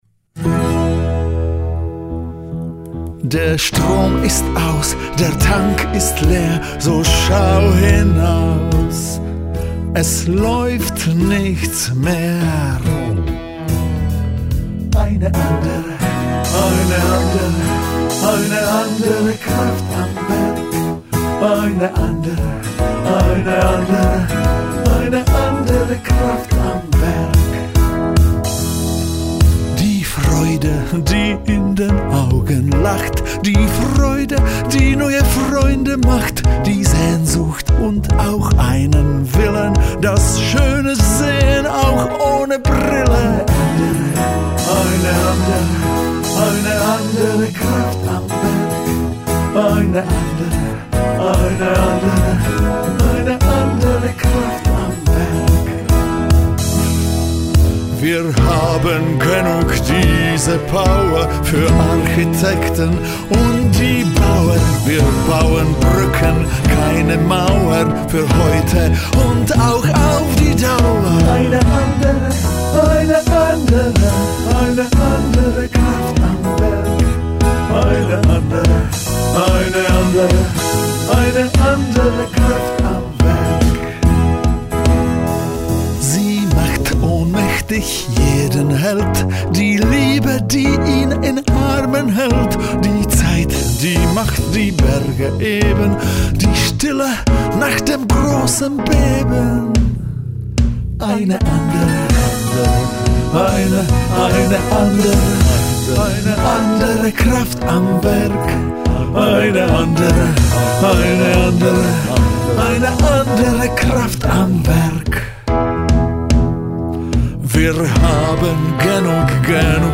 Noten: ...Chor ... Chor+piano